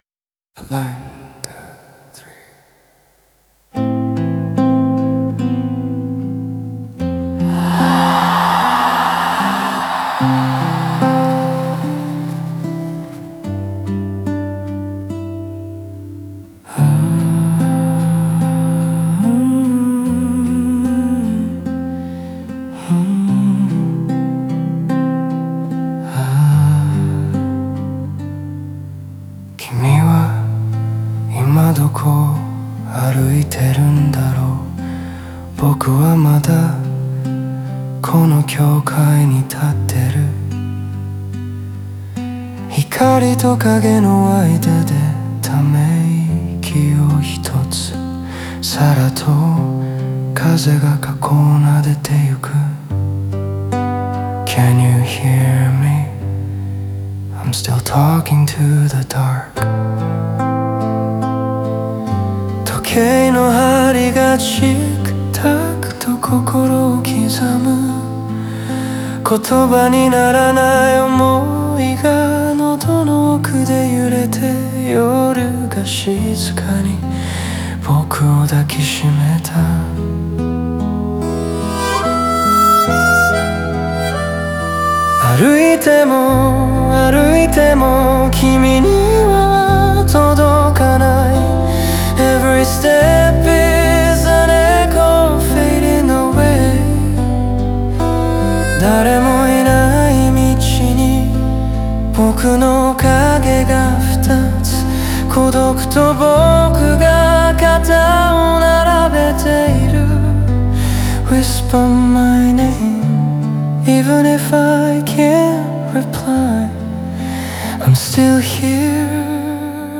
フォークギターの音とともに、夜の静寂の中で心の声が滲み出す。
音の隙間に漂う英語の囁きが、心の奥底の真実をそっと照らし出す、静かで深い独白の詩。